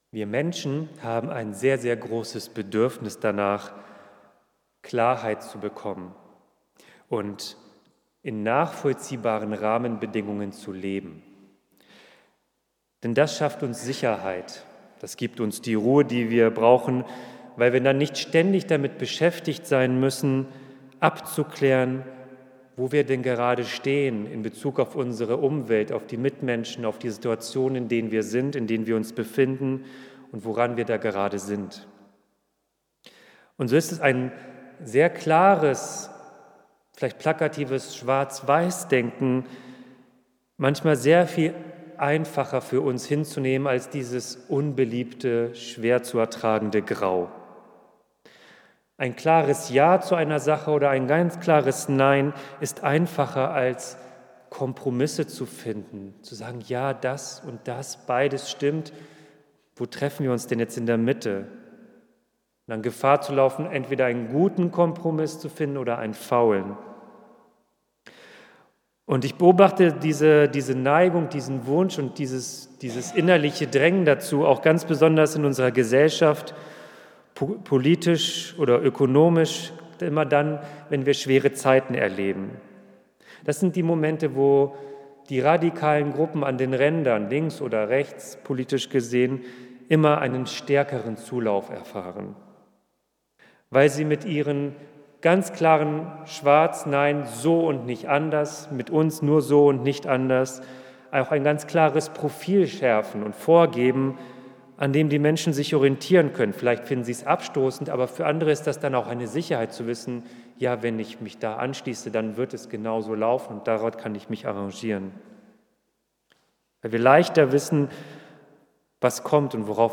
Predigt | Bethel-Gemeinde Berlin Friedrichshain